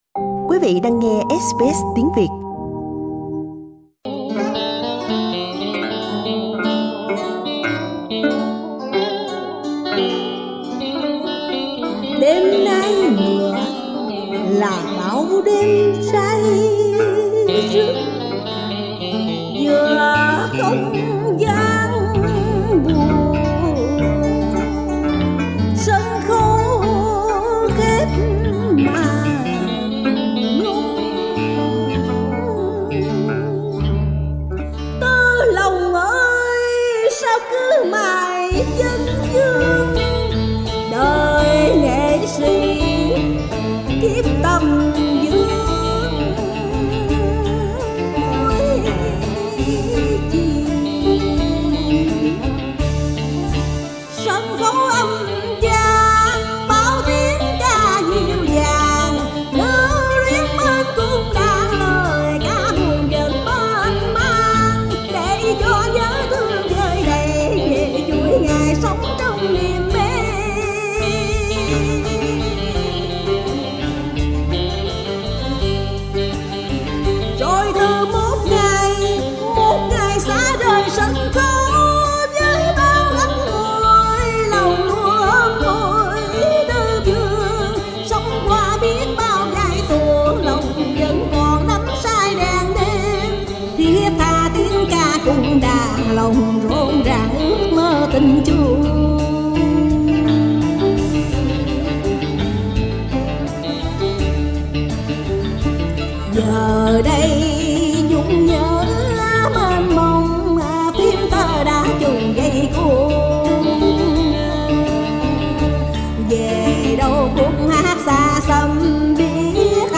Cổ Nhạc Việt Nam tại Úc - Giỗ Tổ Cải Lương